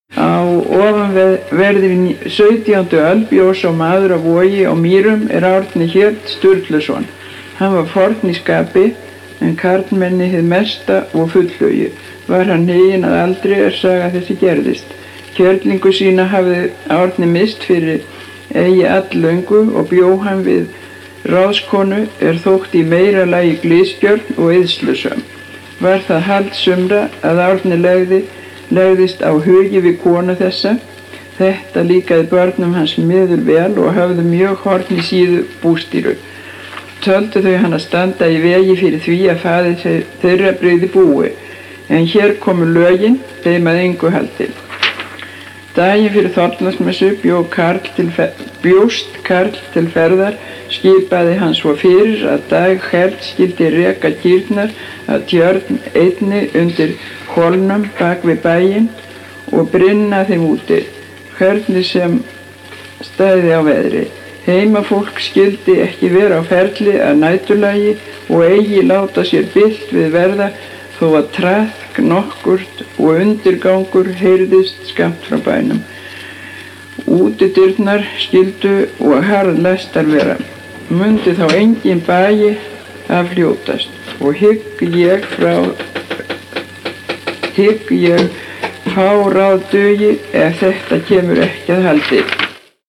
Mállýskudæmi
Vestur-Skaftfellingar
Dæmi 2: Kona les texta um Árna í Vogi á 9. áratug 20. aldar (Málhafi nr. 7 í Mállýskudæmum bls. 35):